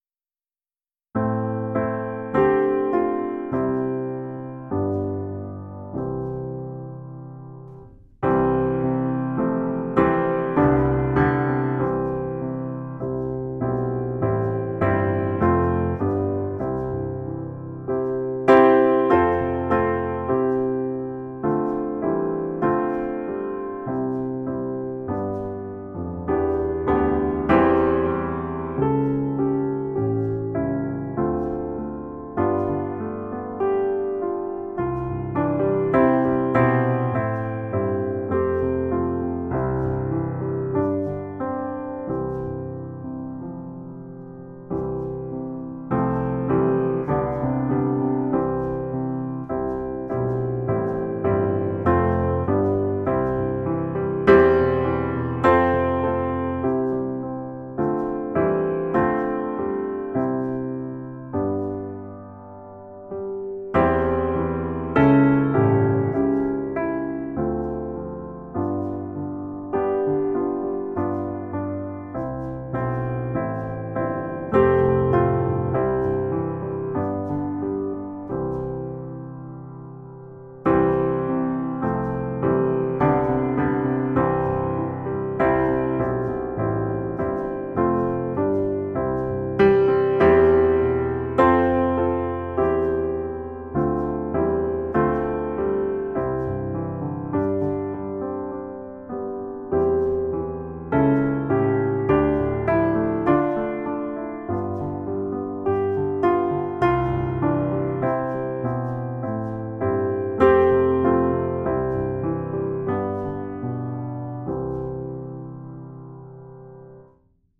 Maa on niin kaunis -säestys